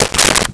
Switchboard Telephone Receiver Up 01
Switchboard Telephone Receiver Up 01.wav